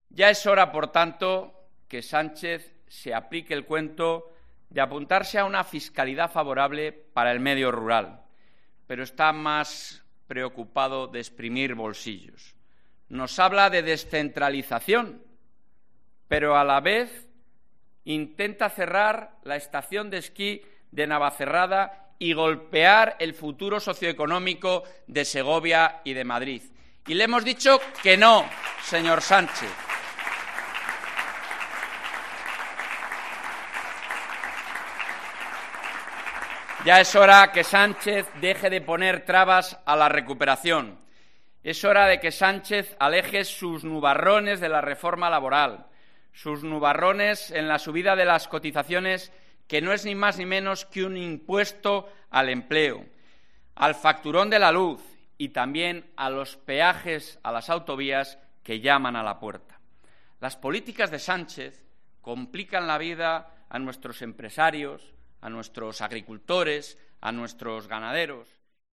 "Le hemos dicho que no", ha afirmado con rotundidad Fernández Mañueco en alusión al posible cierre de las pistas de esquí del puerto de Navacerrada durante su intervención en la Junta Directiva del Partido Popular de Castilla y León.